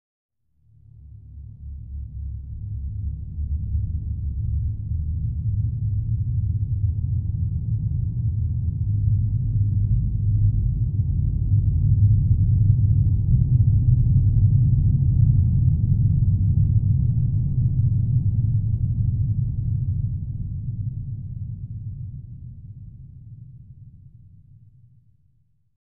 Submarine sails underwater 10
Sound category: Submarine